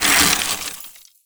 ice_spell_freeze_small_04.wav